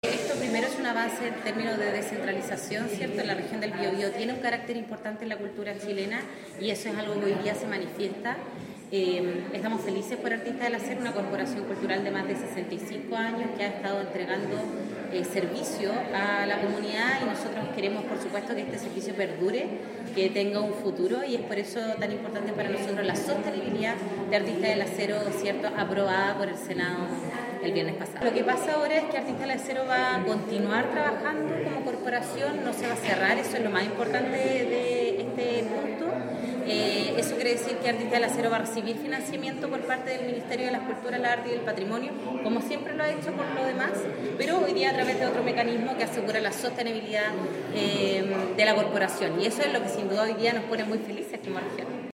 En un punto de prensa, la Seremi de las Culturas, las Artes y el Patrimonio, Paloma Zúñiga, destacó el trabajo desarrollado por Artistas del Acero durante más de seis décadas en la Región del Biobío.